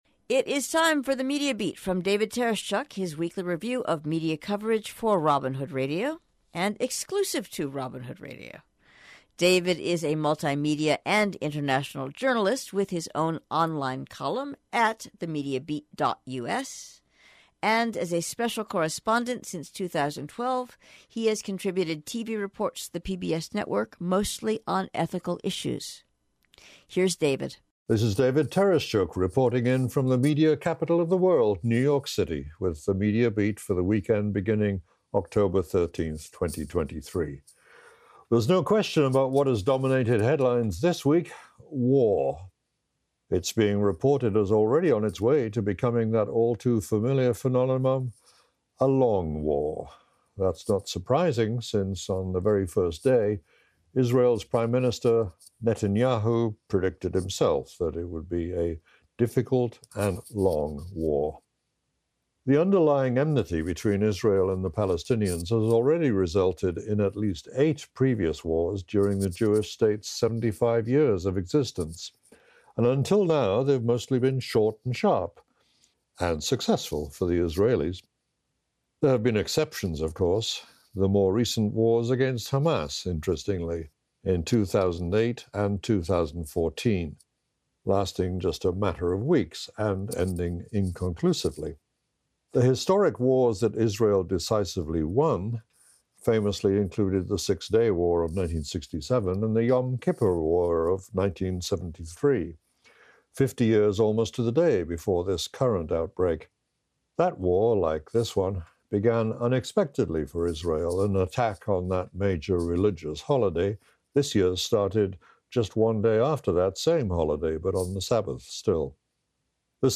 The audio version of THE MEDIA BEAT appears every week exclusively on Robin Hood Radio – first on Friday morning and rebroadcast over the weekend.